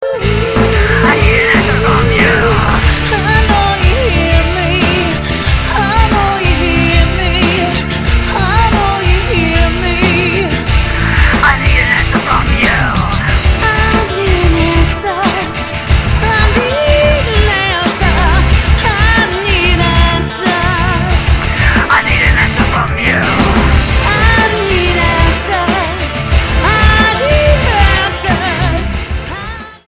STYLE: Pop
With a strong and passionate voice, rich and full